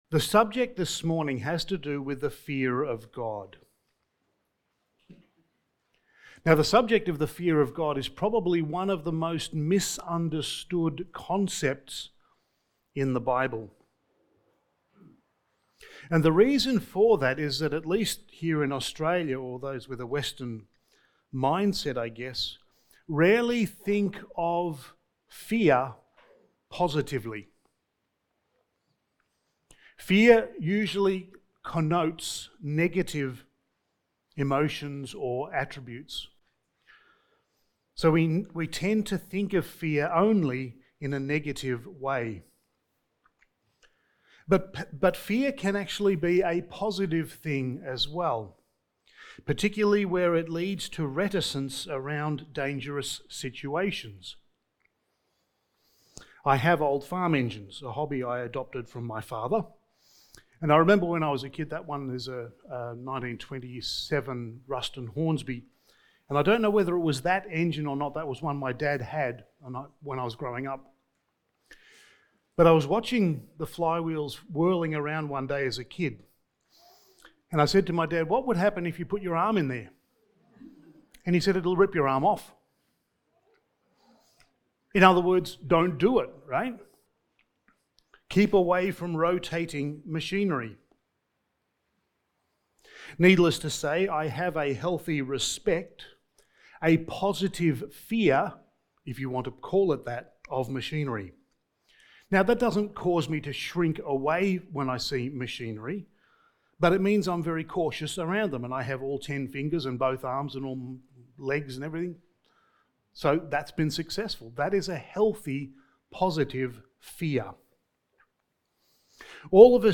Studies in the Book of Ecclesiastes Sermon 9: Worship and the Fear of God
Service Type: Sunday Morning